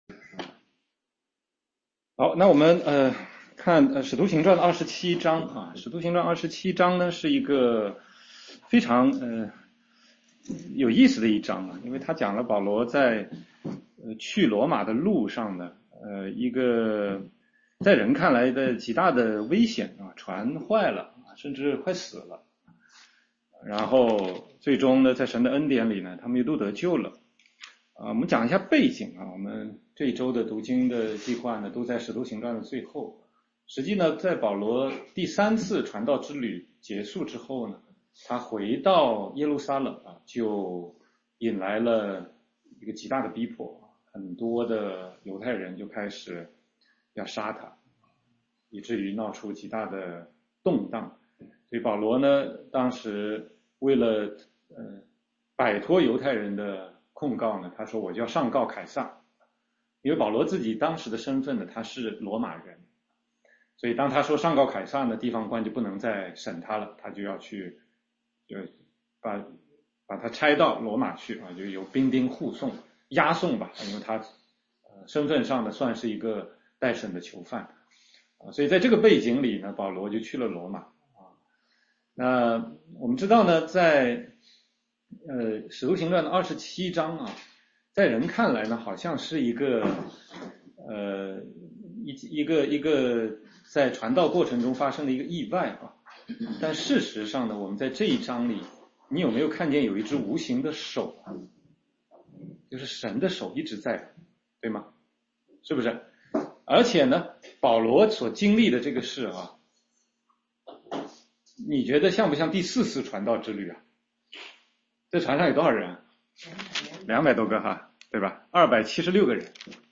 全中文查经